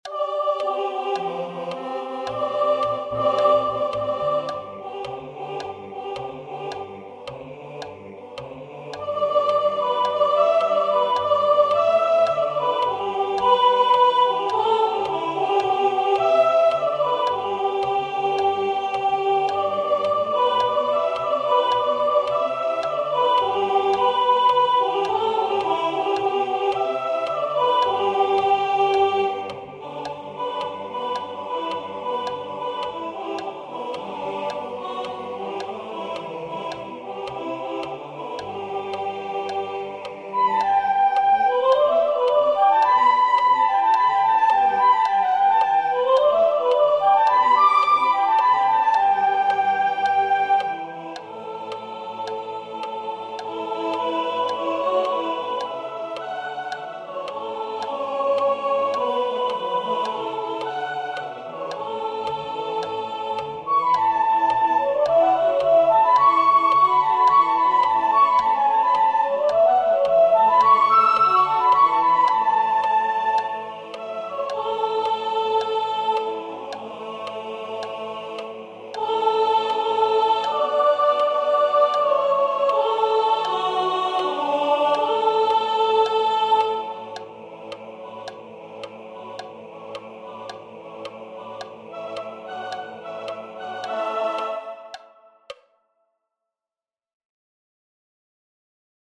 This page contains rehearsal files for choir members.